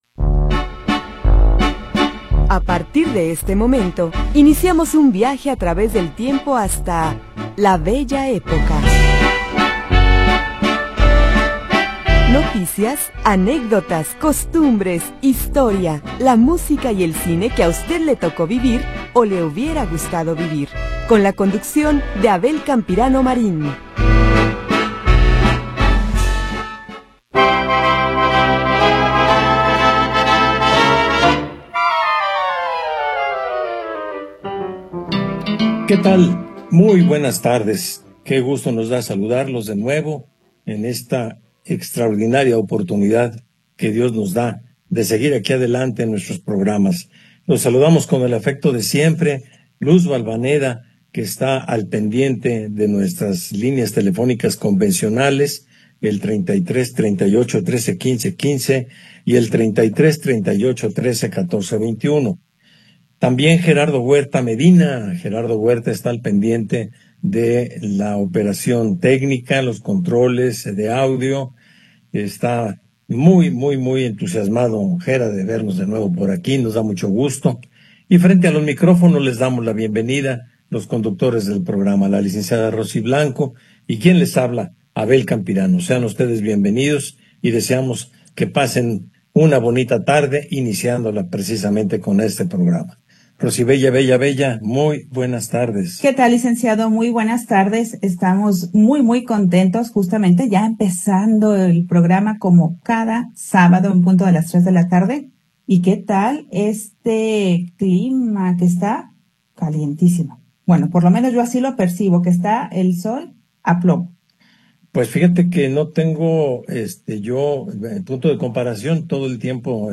Anécdotas, comentarios, música y noticias de ayer y hoy